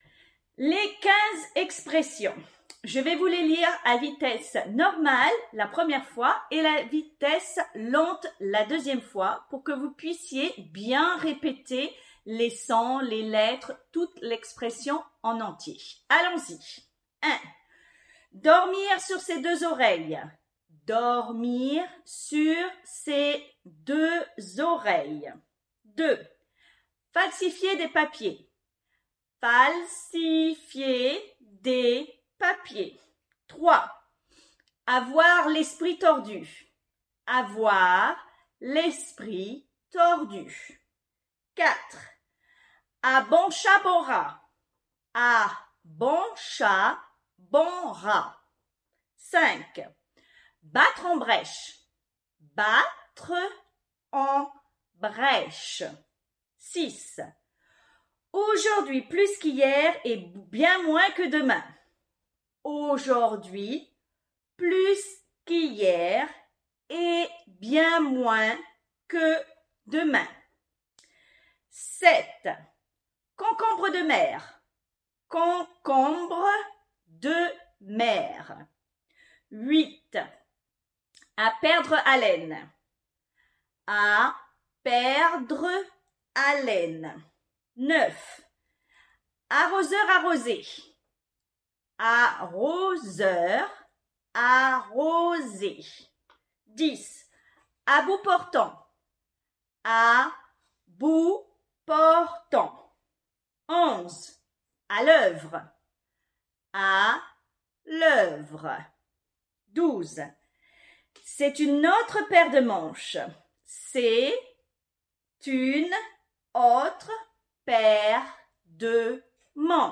Moi, je trouve la plupart des phrases en vitesse normale difficile à entendre sans concentrer : « pacifier des papiers » , « abonne chat bon rat » 🙁